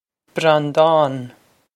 Breandán Bran-dahn
This is an approximate phonetic pronunciation of the phrase.